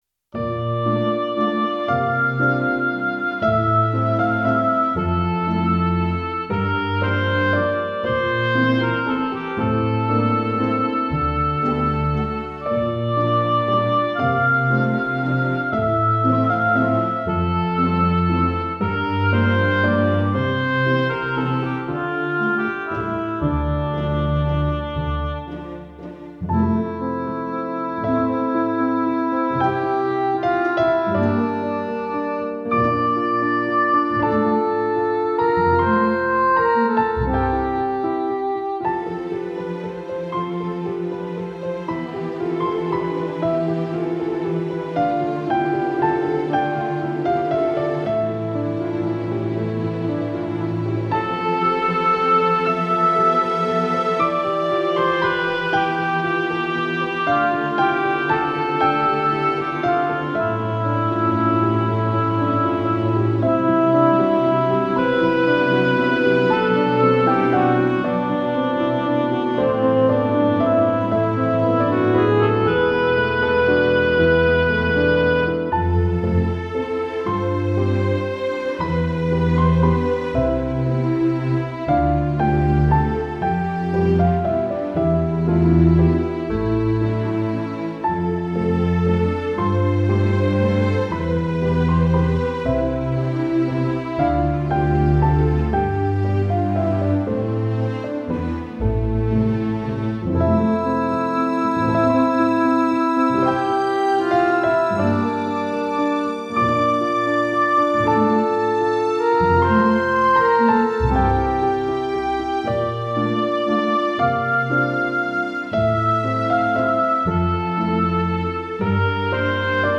听见钢琴里的四季